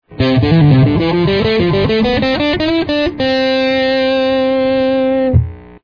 C Major Scale on the Guitar
Play Scale Fast |